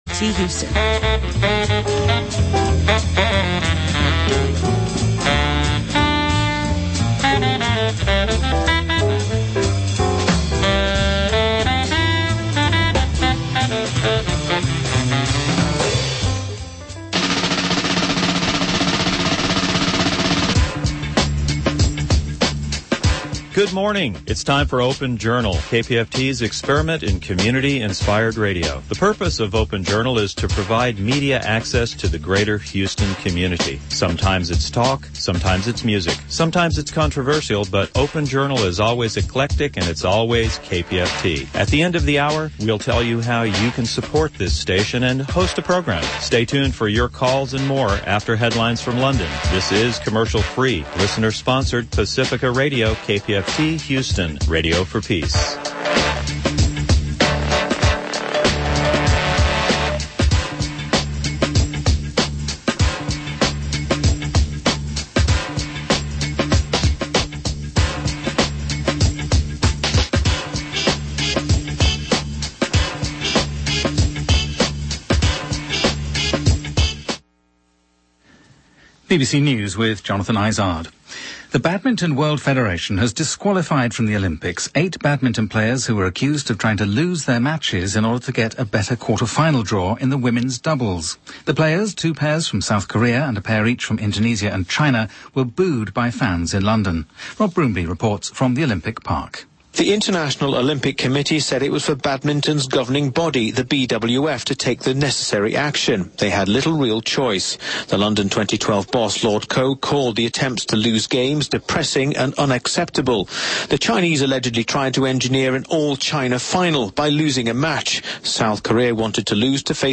We take callers during this show.